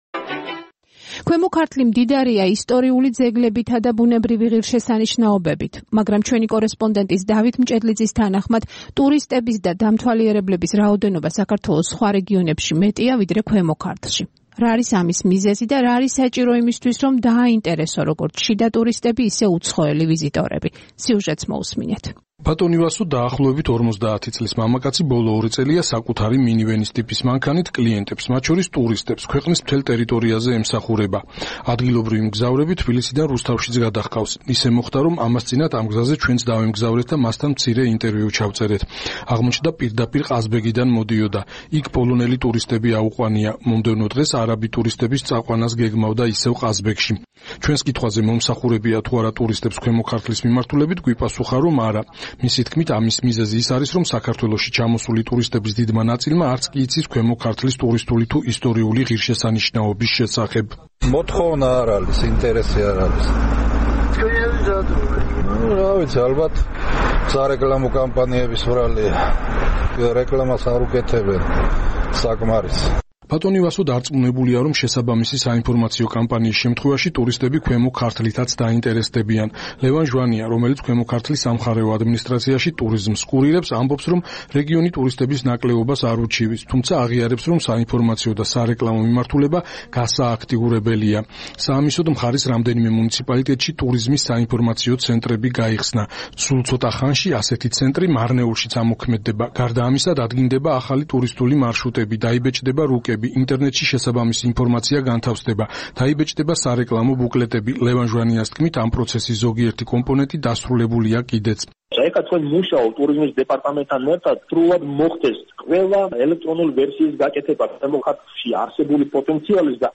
რადიო თავისუფლება რადიო თავისუფლება